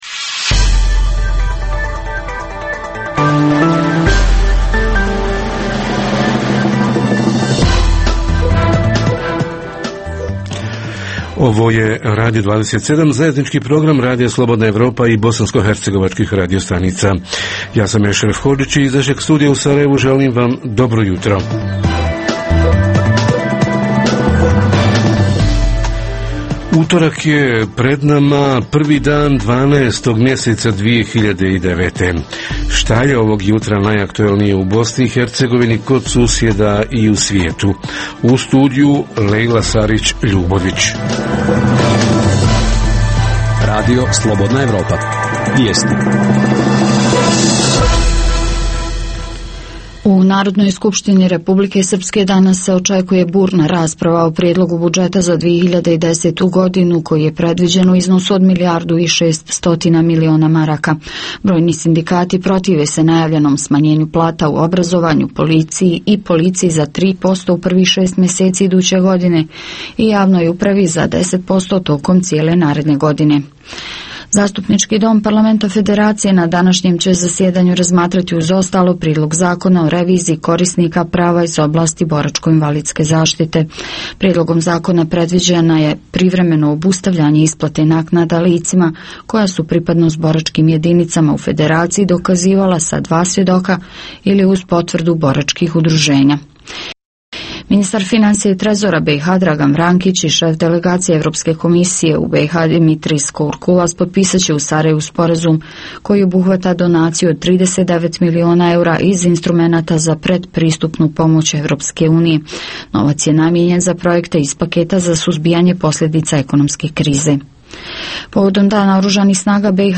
Budžeti lokalnih zajednica za 2010. godinu – šta je realno očekivati na prihodnoj i kako realno planirati rashodnu stranu? Reporteri iz cijele BiH javljaju o najaktuelnijim događajima u njihovim sredinama.
Redovni sadržaji jutarnjeg programa za BiH su i vijesti i muzika.